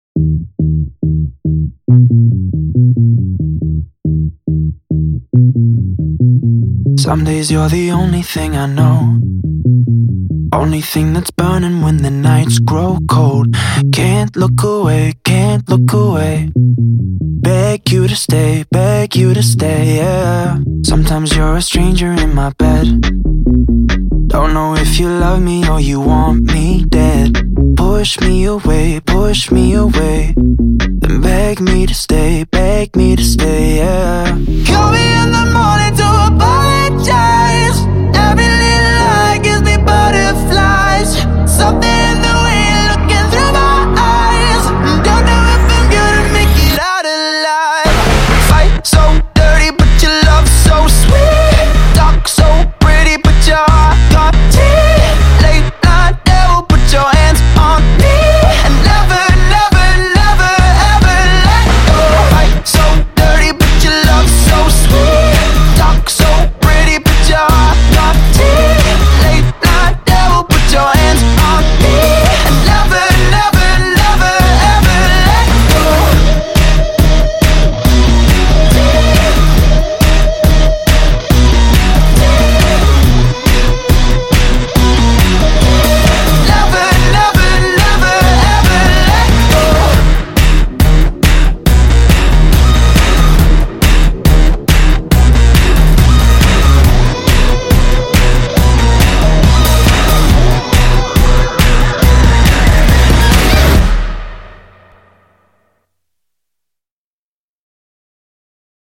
BPM139